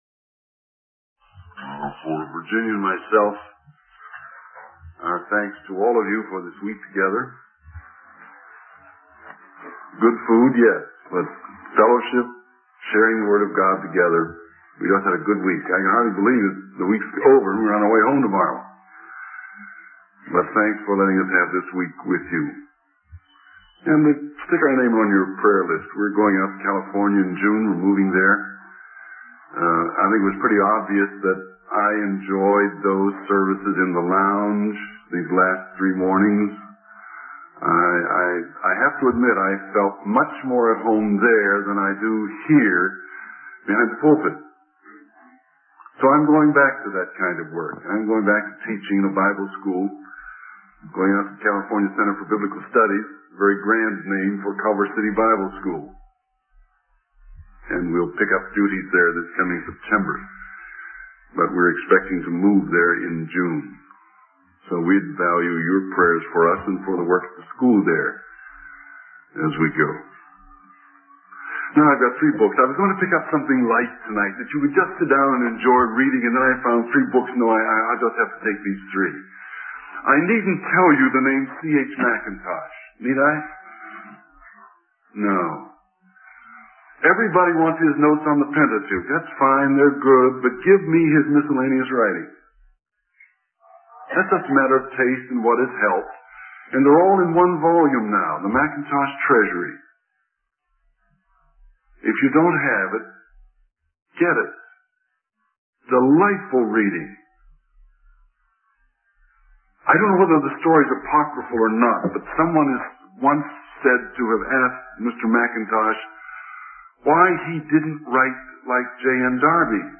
In this sermon, the preacher emphasizes the importance of loving God with all our heart, soul, mind, and strength, as well as loving our neighbor. He criticizes the religious leaders for prioritizing religious rituals over these two commandments. The preacher also highlights the story of the widow who gave all she had as an example of true devotion to God.